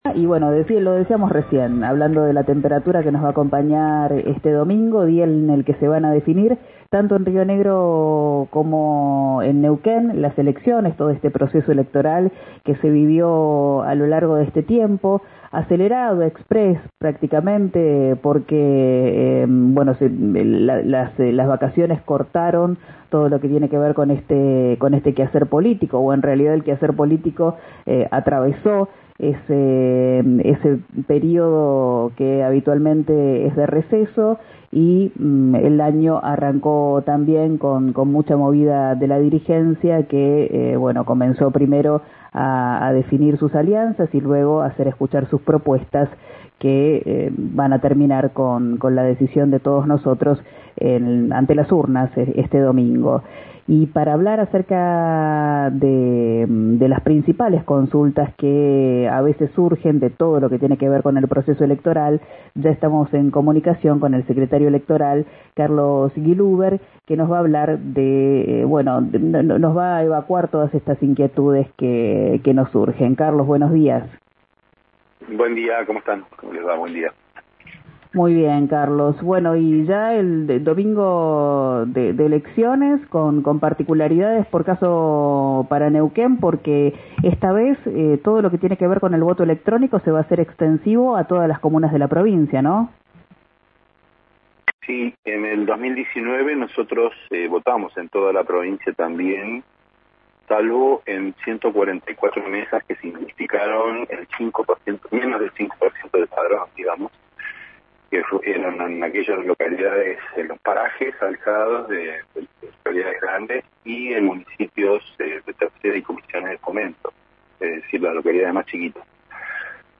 El secretario electoral, Carlos Willhuber, en dialogo con RADIO RIO NEGRO respondió y aclaró las principales dudas que pueden surgir a la hora de ir a votar.